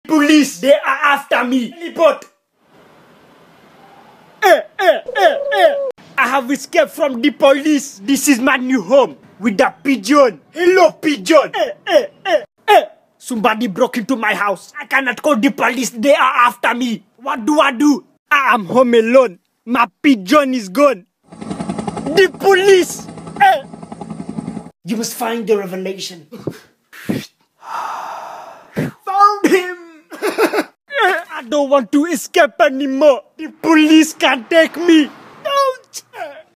PIGEON EH EH EH EH sound effects free download